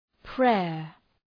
Προφορά
{preər}